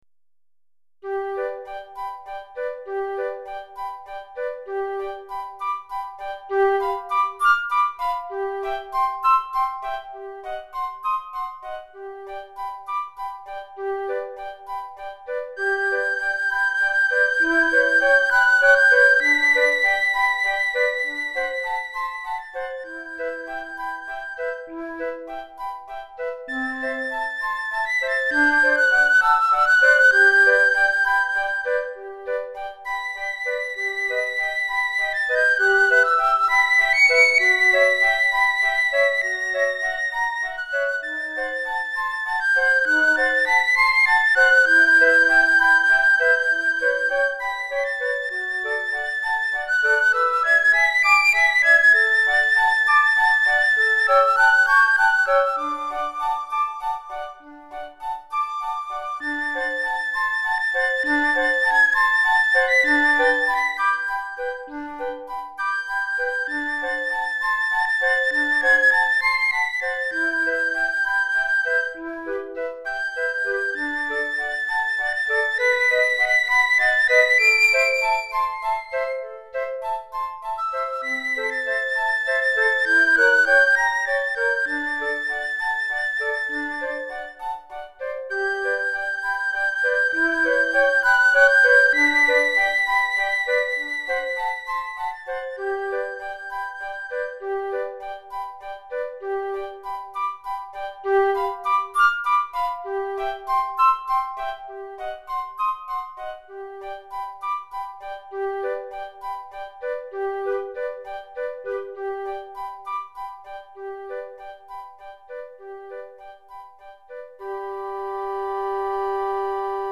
Répertoire pour Flûte à bec - Piccolo et 3 Flûtes